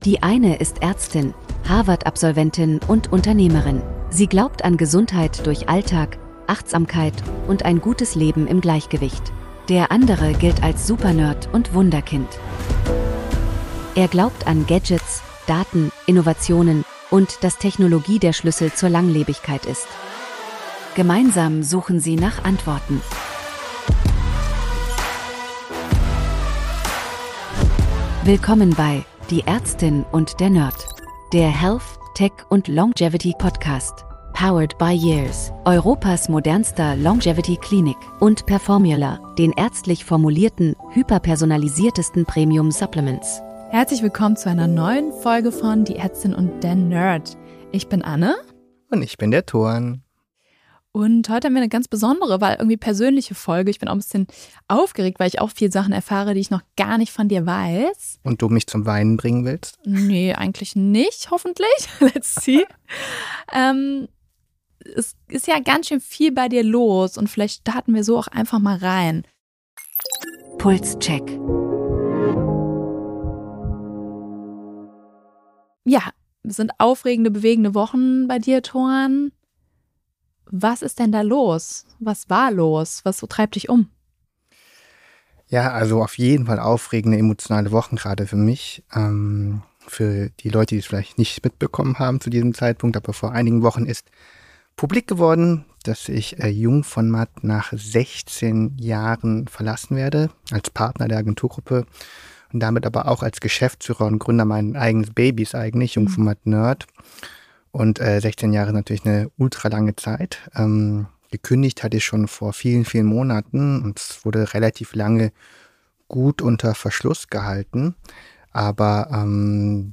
Eine ruhige Folge, in der weniger gestritten wird, aber viel offengelegt.